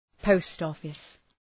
Προφορά
{‘pəʋst,ɒfıs}